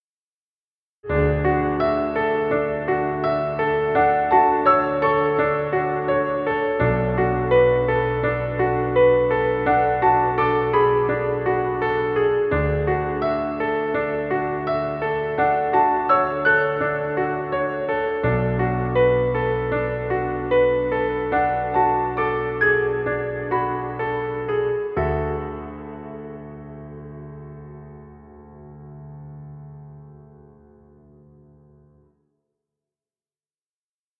Emotional piano music.
Stock Music.